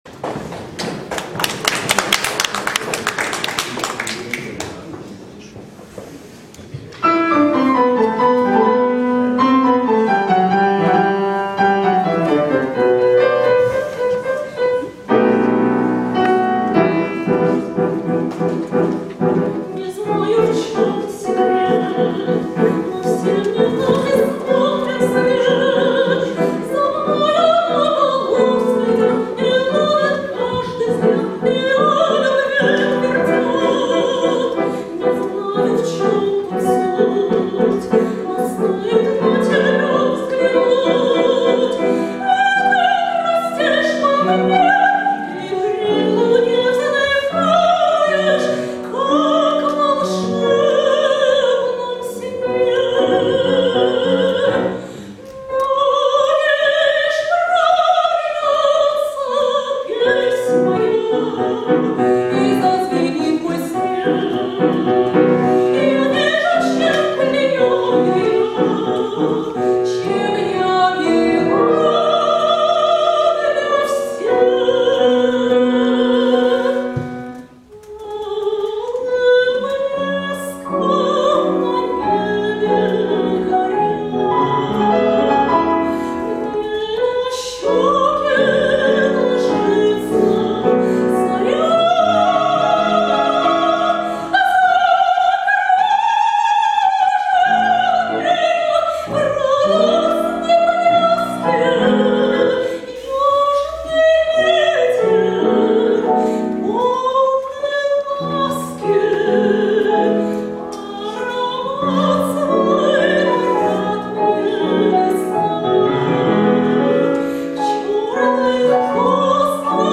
Ария